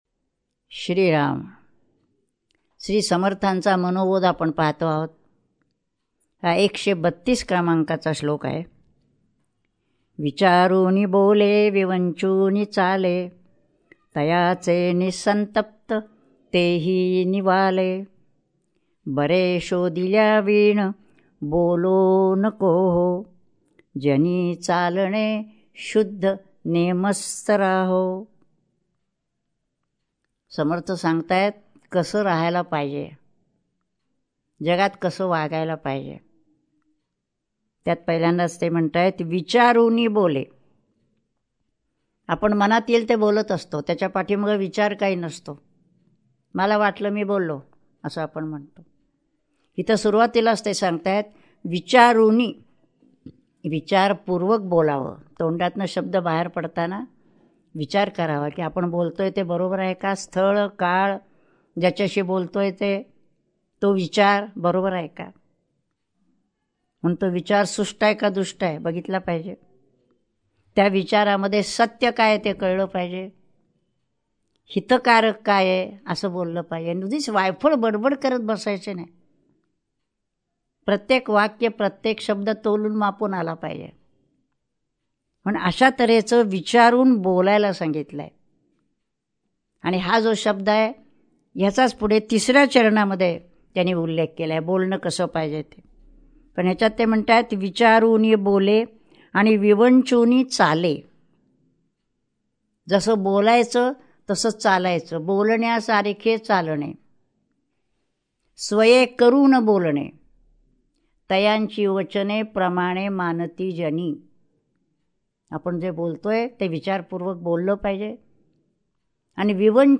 श्री मनाचे श्लोक प्रवचने श्लोक 133 # Shree Manache Shlok Pravachane Shlok 133